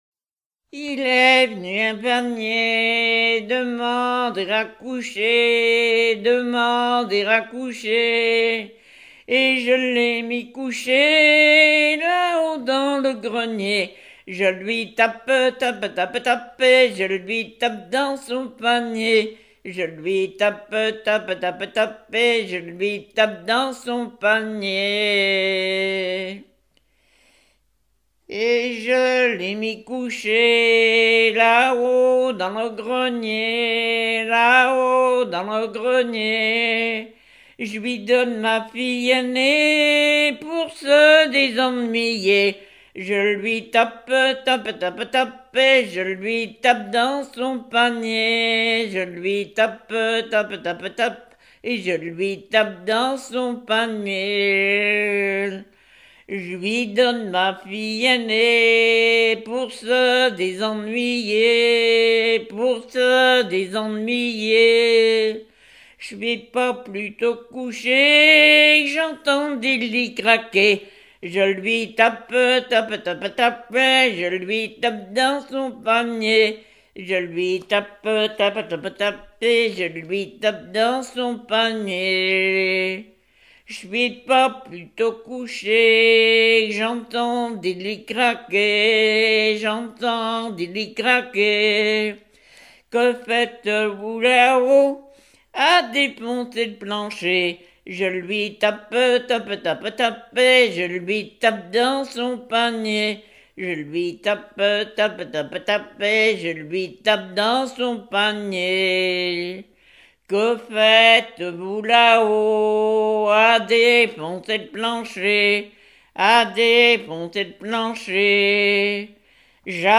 Genre laisse
Catégorie Pièce musicale éditée